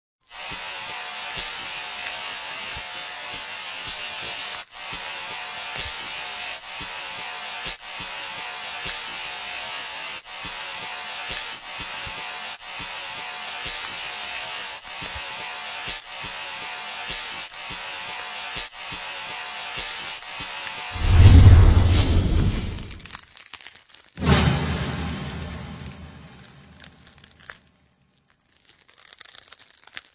Tags: martillo